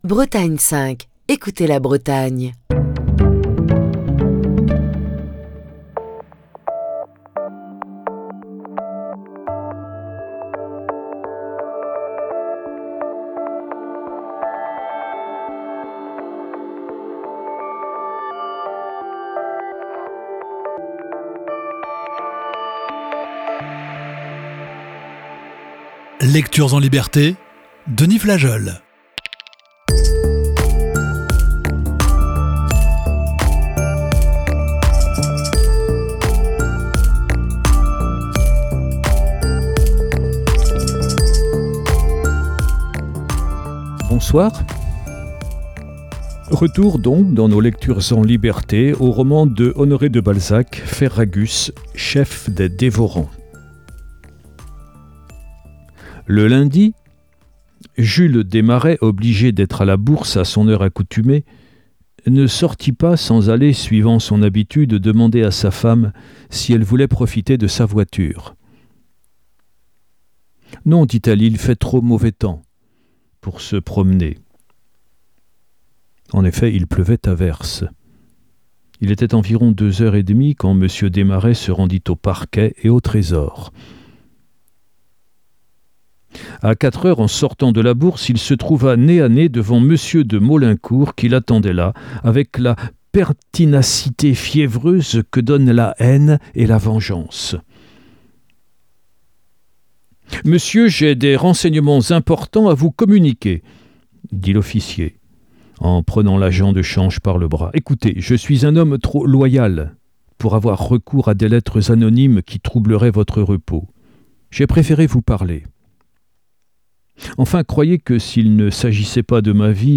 Émission du 28 mai 2024.